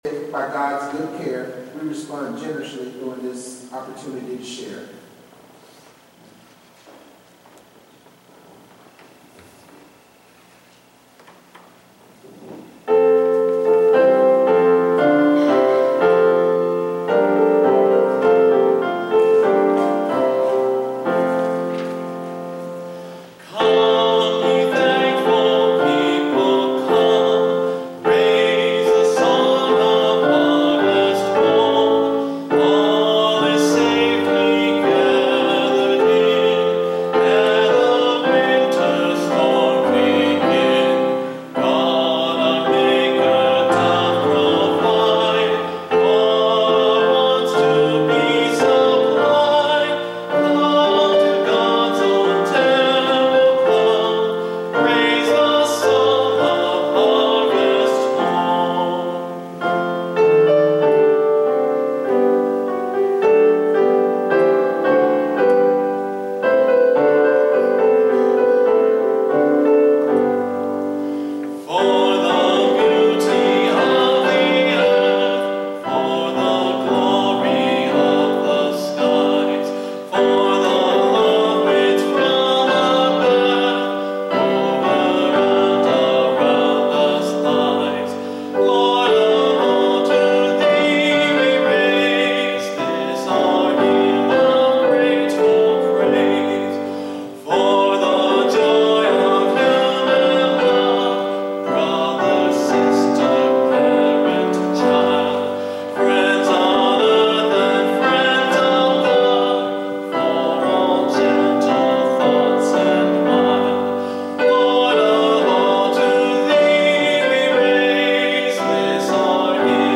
Here is the lyric and sound tracks for the Medley “With a Song of Thanksgiving” that I sang Sunday at Avondale United Methodist Church.